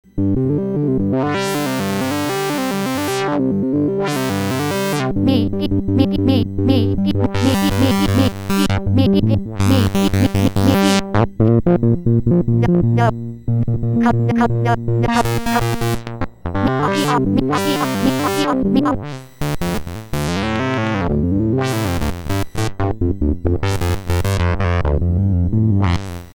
Quick demo sound
- 3 voice polyphony
- support for 2nd SID chip (stereo chorus effects)